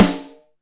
FREAK RIM.wav